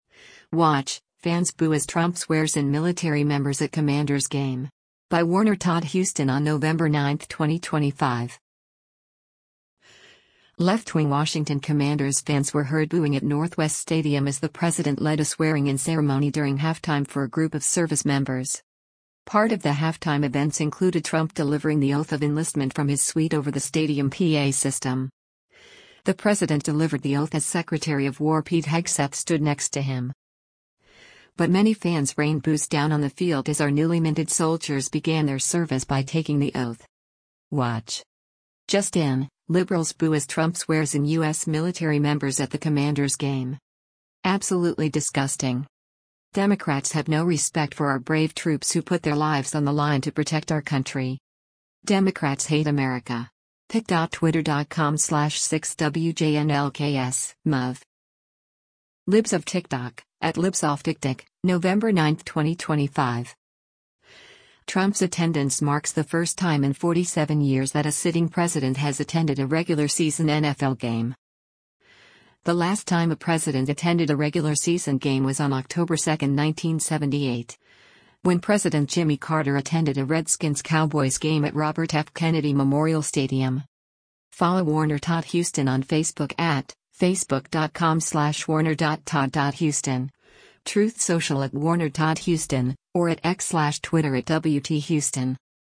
Left-wing Washington Commanders fans were heard booing at Northwest Stadium as the president led a swearing-in ceremony during halftime for a group of service members.
Part of the halftime events included Trump delivering the oath of enlistment from his suite over the stadium PA system.
But many fans rained boos down on the field as our newly minted soldiers began their service by taking the oath.